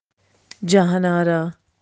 The first is a Pakistani friend:
The first example seems normal to me: “Jahan ara” with the n pronounced